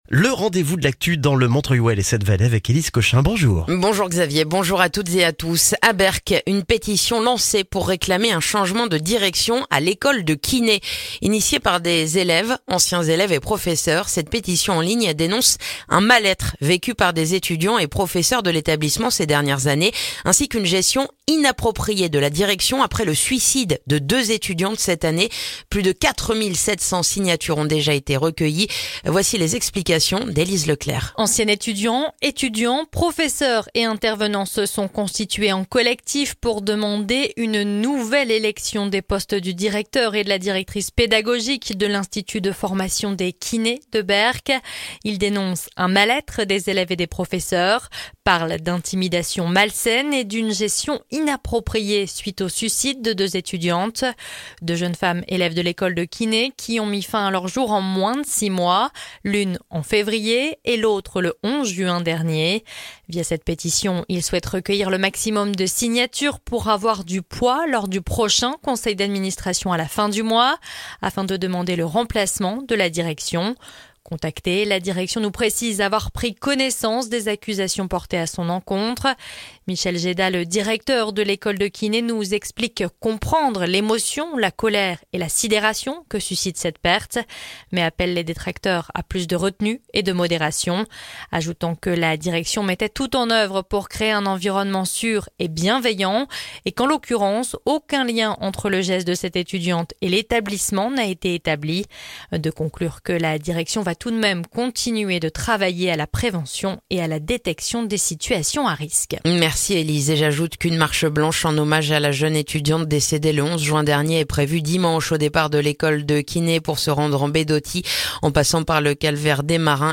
Le journal du vendredi 21 juin dans le montreuillois